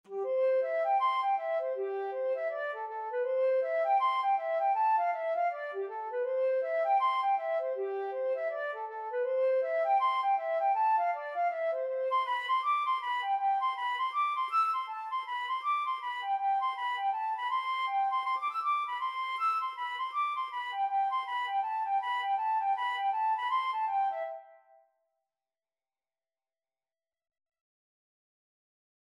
Flute version
C major (Sounding Pitch) (View more C major Music for Flute )
4/4 (View more 4/4 Music)
G5-E7
Flute  (View more Intermediate Flute Music)
Traditional (View more Traditional Flute Music)